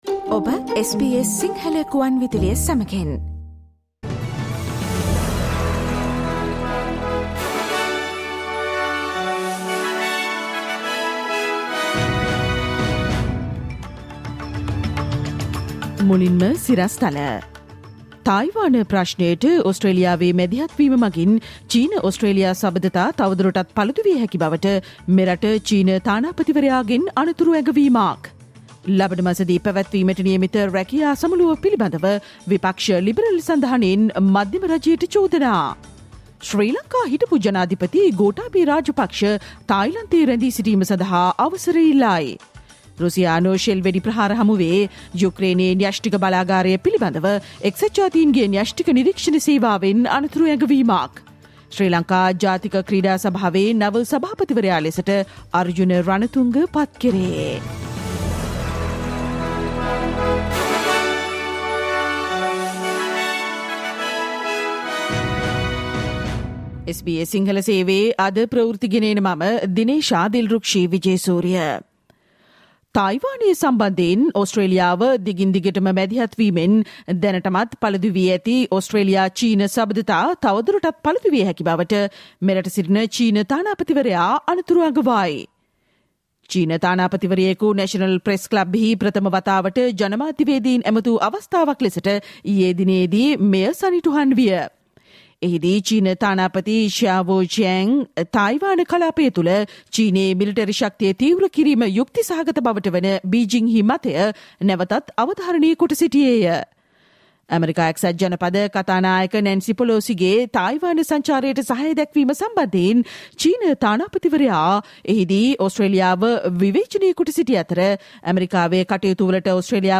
Listen to the SBS Sinhala Radio news bulletin on Thursday 11 August 2022